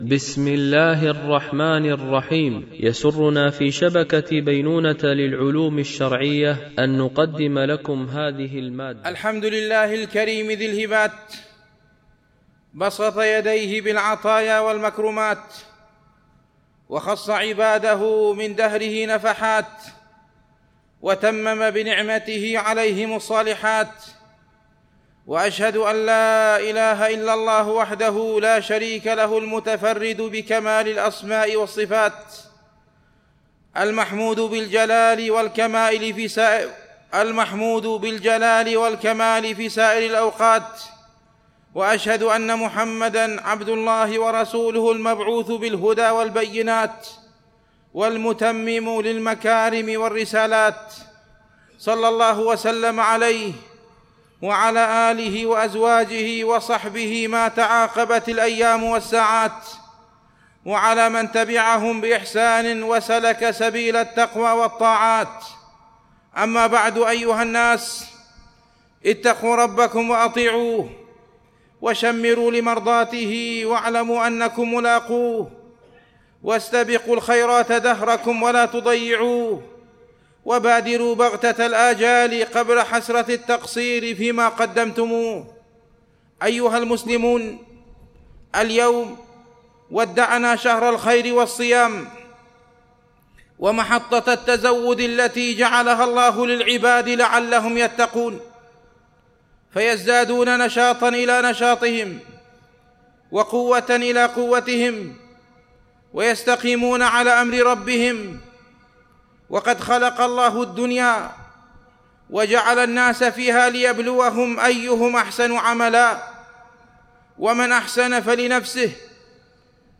خطبة الجمعة ١ شوال ١٤٤٤ هـ